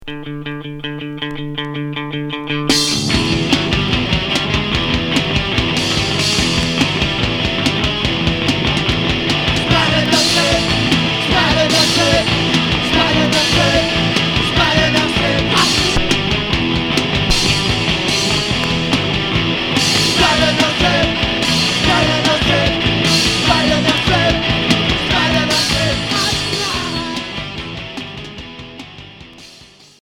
Garage punk